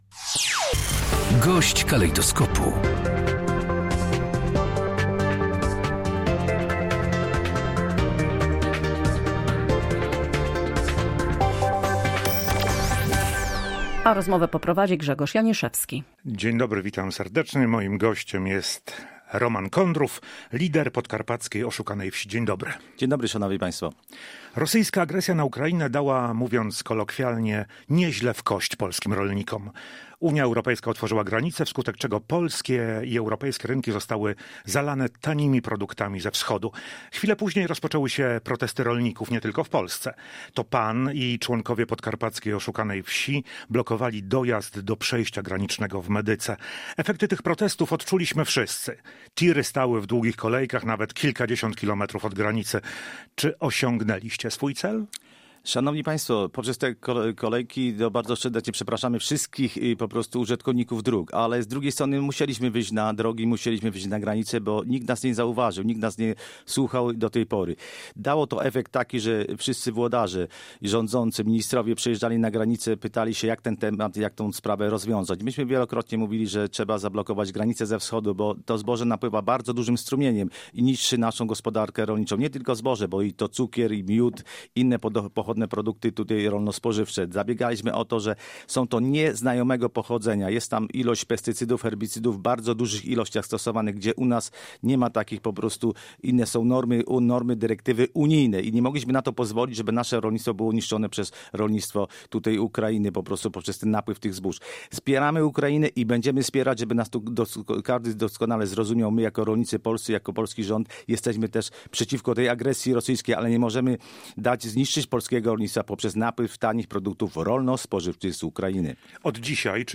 Gość dnia • Koniec bezcłowego handlu z Ukrainą.